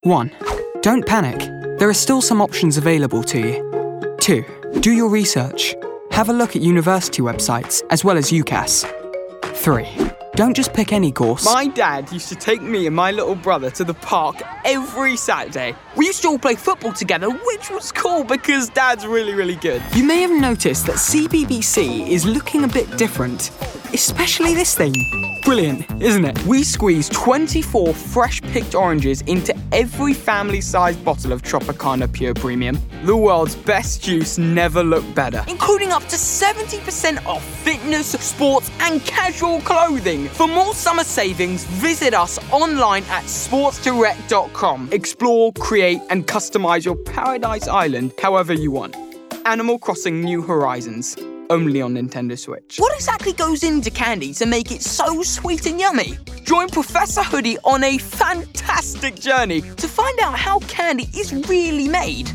Male
Commercial Showreel
Straight
Commercial, Upbeat, Energy, Light